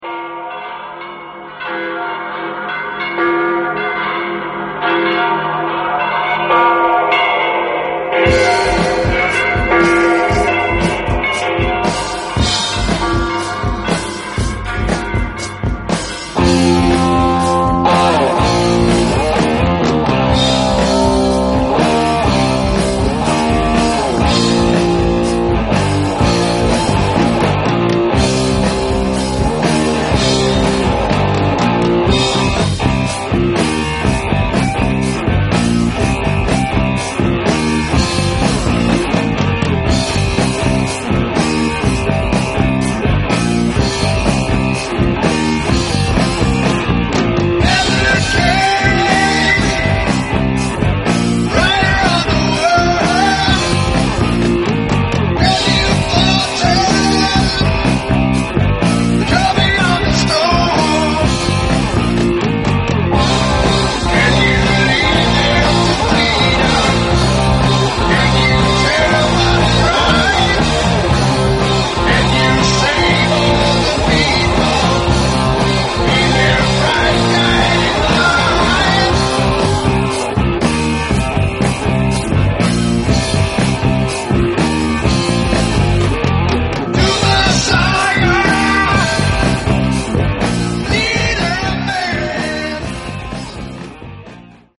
hard rock band